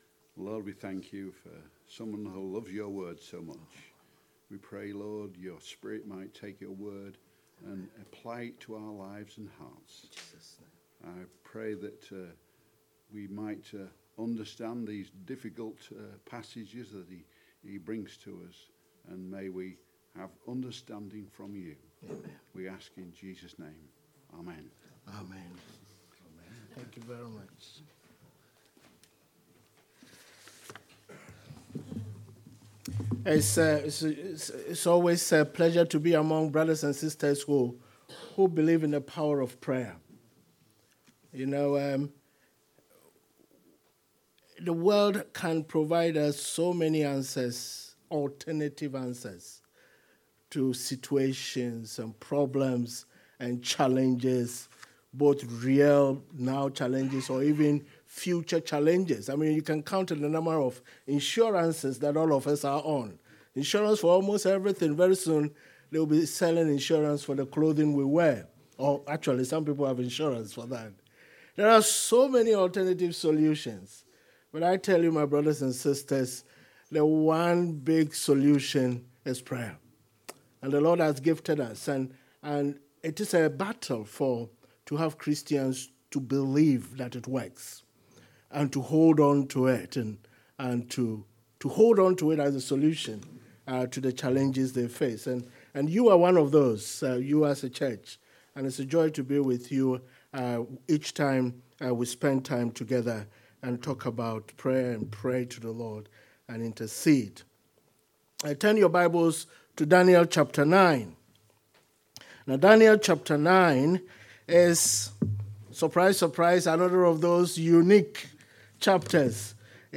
exposition of Daniel 9:1-3